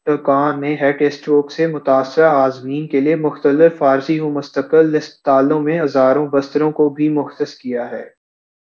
deepfake_detection_dataset_urdu / Spoofed_TTS /Speaker_13 /15.wav